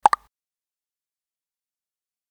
Звук оповещения ВКонтакте